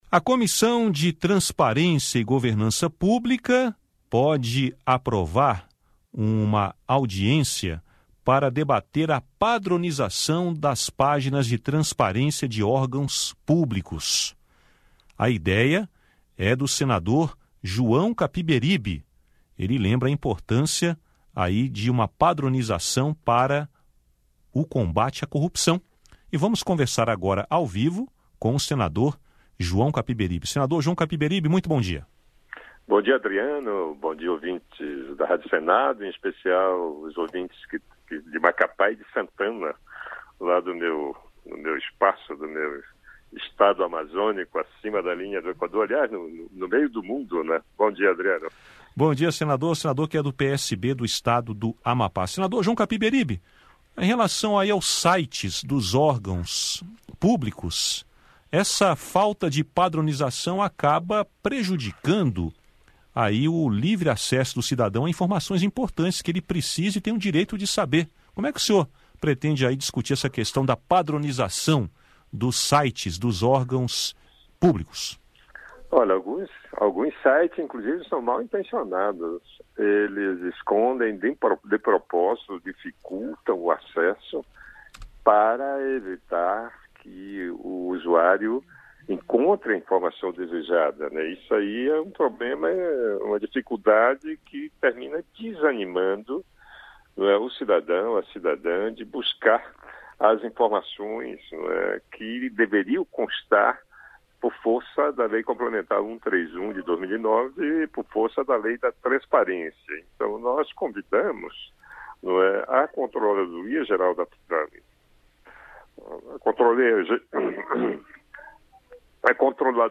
O parlamentar conversou sobre o assunto e também sobre o processo de impeachment da presidente Dilma Rousseff com